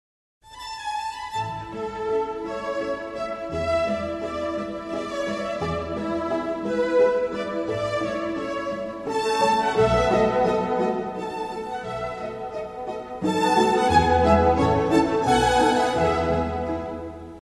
Und wenn, dann verbindet man seinen Namen nur mit dem leider völlig kaputtgespielten Menuett aus dem Violinenquartett Nr. 5 in E-Dur.
hier für die ersten Akkorde und den Aha-Effekt.